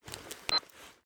pda_draw.ogg